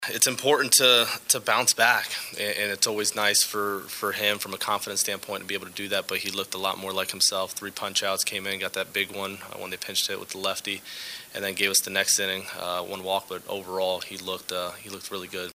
Cards Manager Oliver Marmol on relief pitcher Giovanny Gallegos getting through one and a third scoreless innings after allowing five runs his last time out.